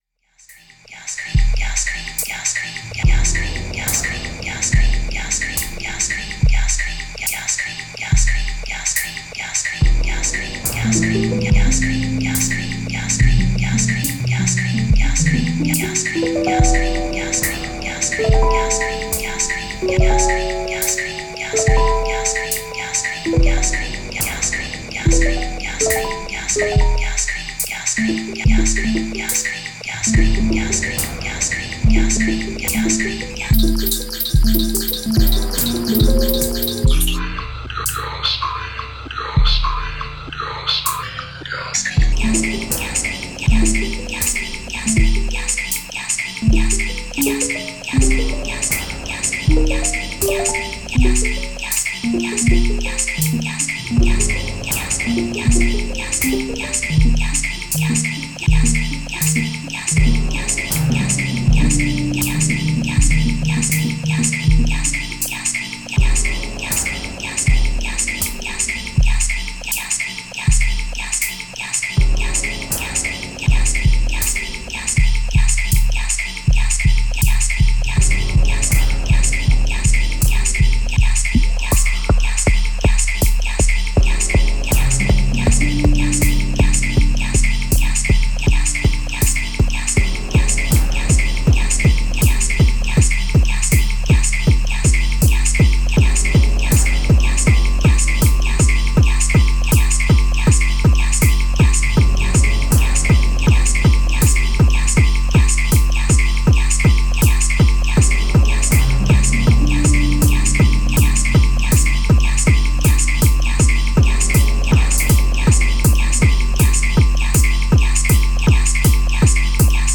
this audio results from his manipulation of a particular environment of looping samples.